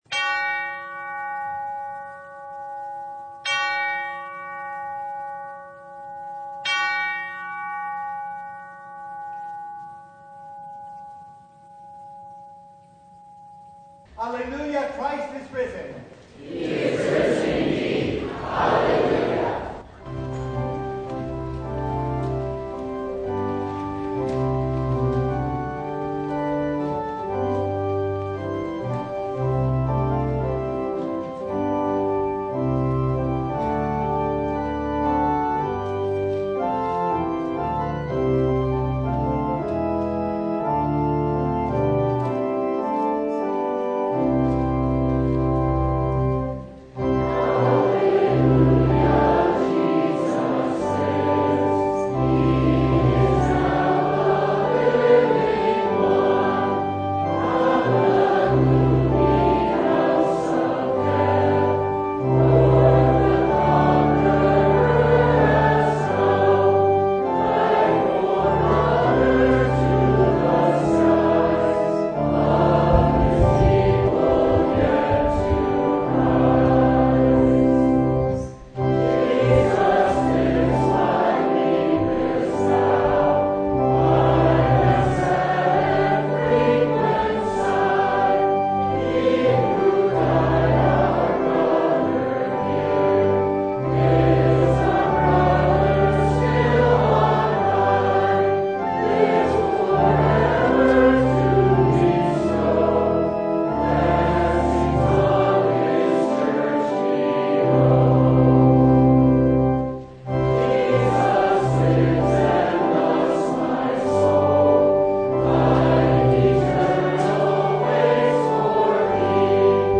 Acts 17:16-31 Service Type: Sunday St. Paul was provoked and distressed when he saw that Athens was full of idols.